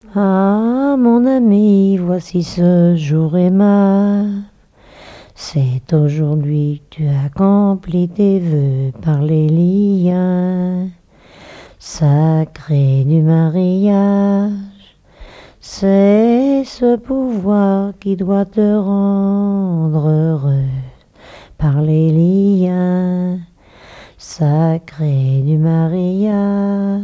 collecte en Vendée
répertoire de chansons de noces
Pièce musicale inédite